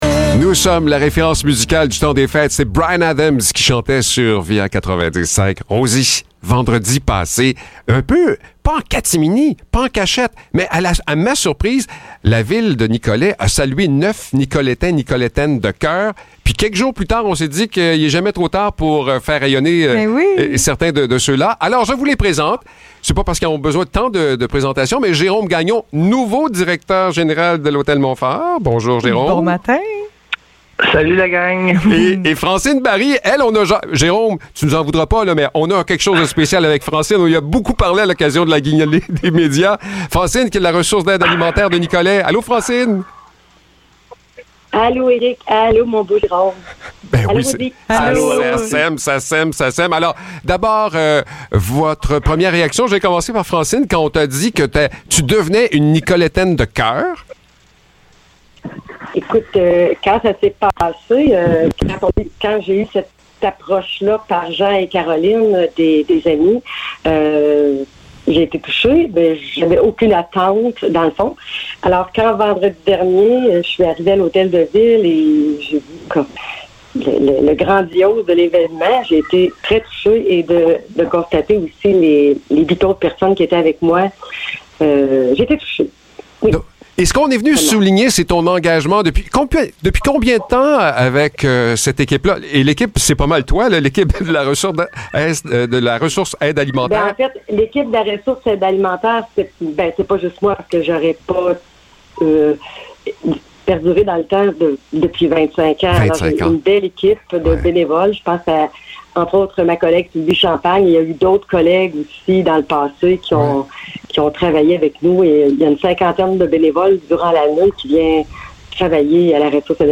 Entrevue avec 2 Nicolétains de coeur